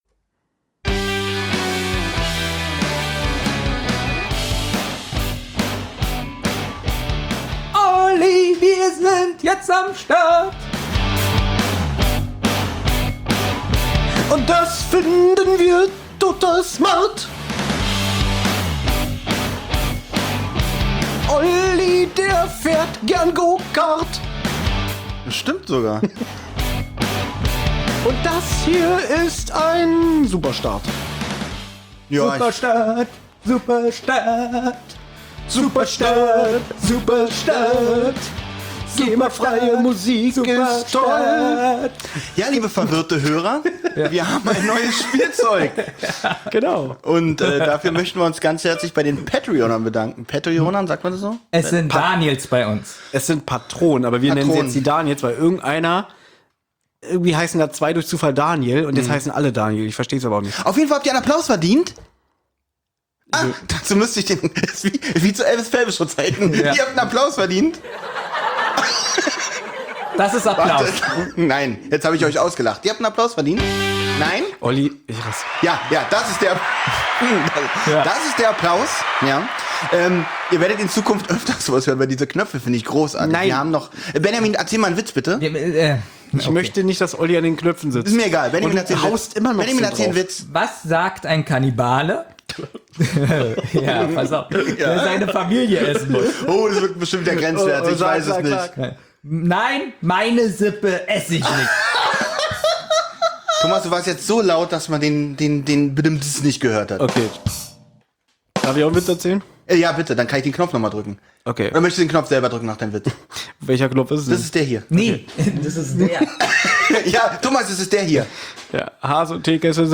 Drei Jungs aus Berlin, die sich regelmässig zusammensetzen und gemeinsam über die Abenteuer der drei berühmten Detektive aus Rocky Beach sprechen.